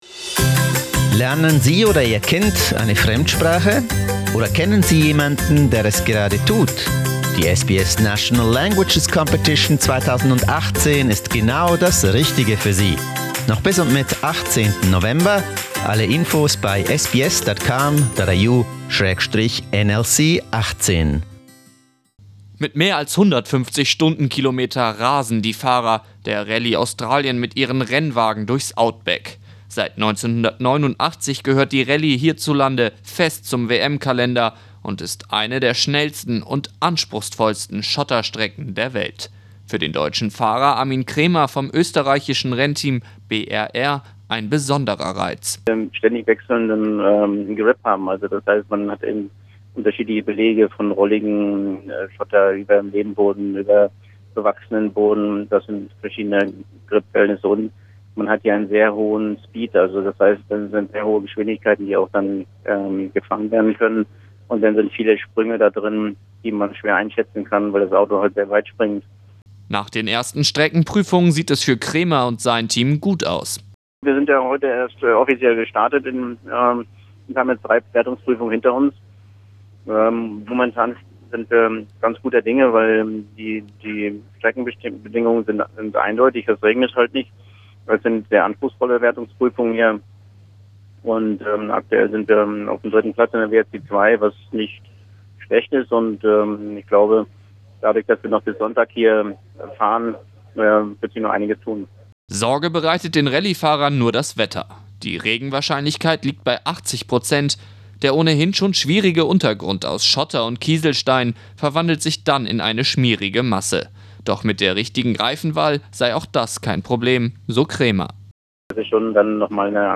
SBS Radio interviews German driver